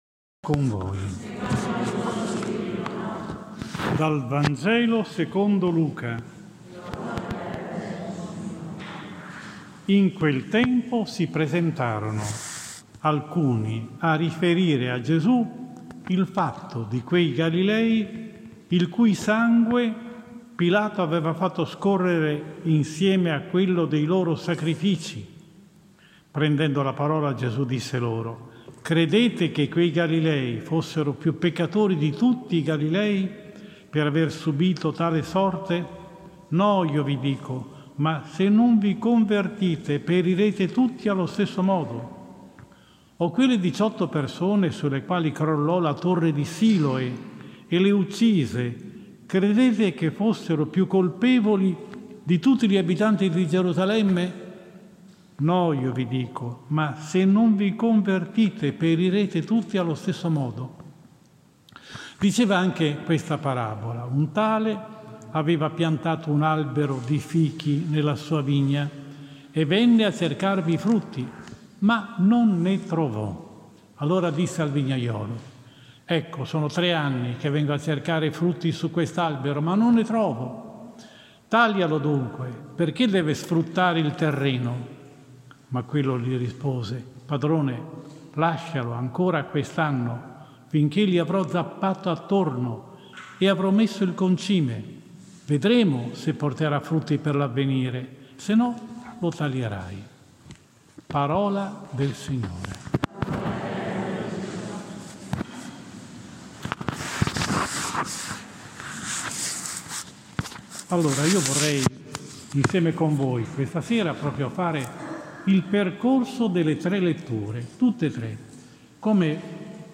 20 Marzo 2022 III DOMENICA di QUARESIMA, Anno C: omelia